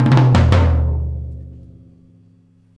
Track 02 - Toms 01.wav